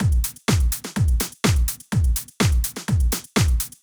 Drumloop 125bpm 02-A.wav